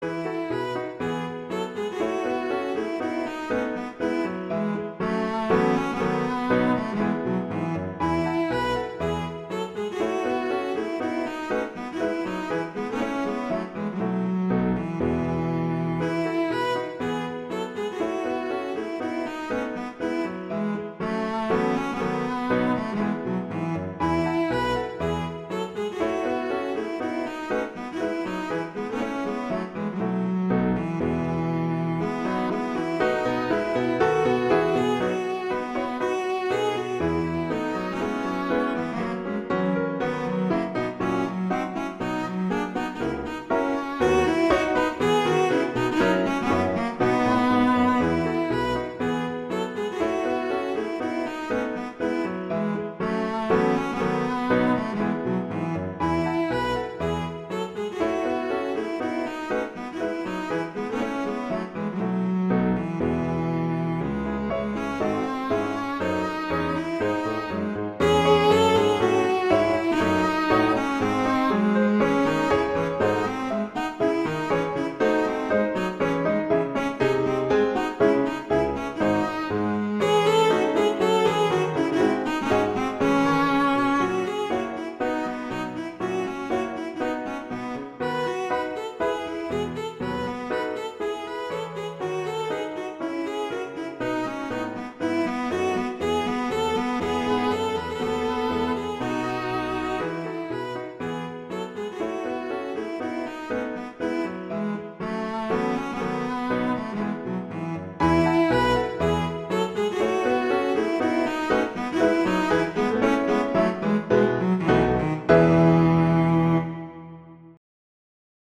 cello and piano
classical
Tempo di Gavotta